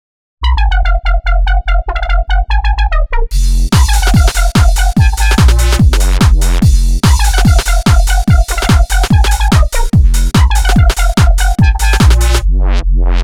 beat buck bumble bumblecore clubby uk garage
rejected 2nd drop sketch